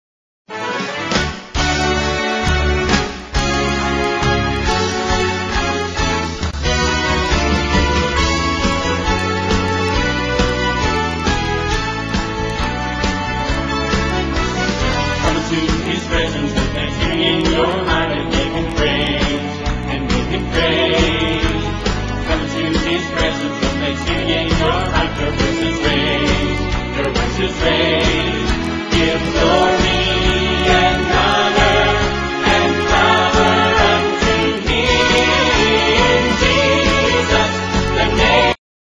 4 tracks WITH BACKGROUND VOCALS